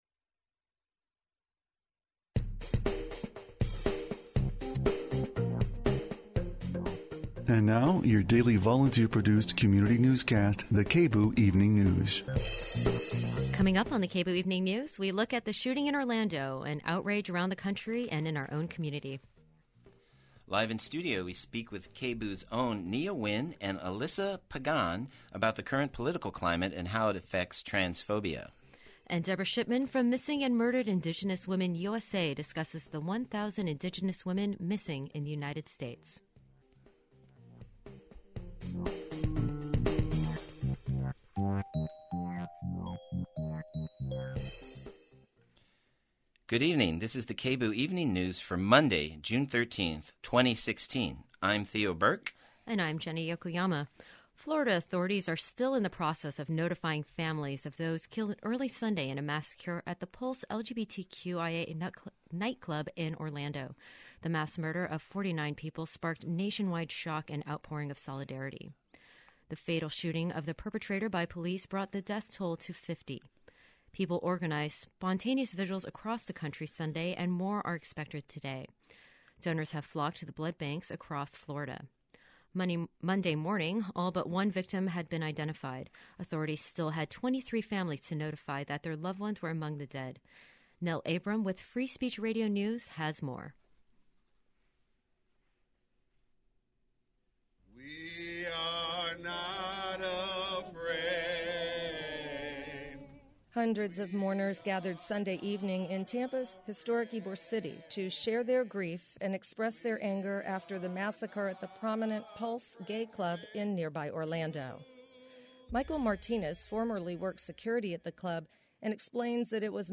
Tonight's newscast features students from the Portland State University Student Union reacting to this weekend's massacre in a Florida nightclub.